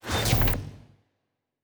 pgs/Assets/Audio/Sci-Fi Sounds/Doors and Portals/Door 8 Close 1.wav at master
Door 8 Close 1.wav